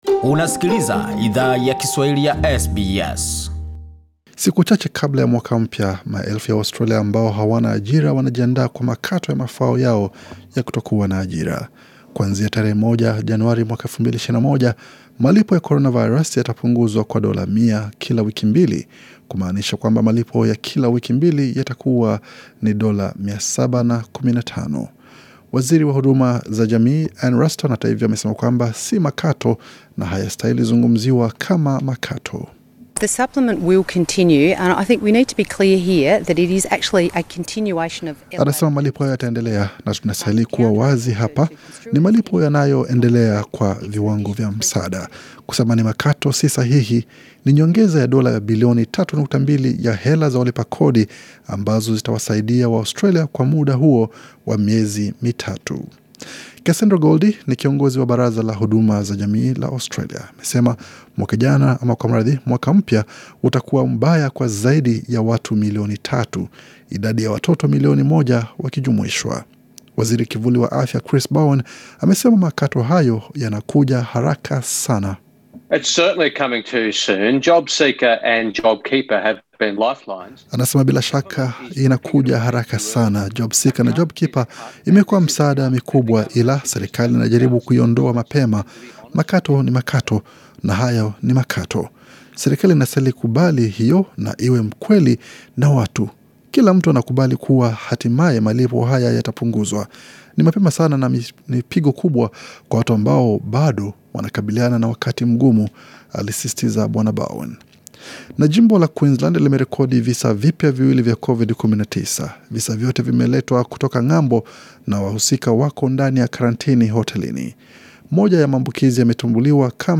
Taarifa ya habari 29 Disemba 2020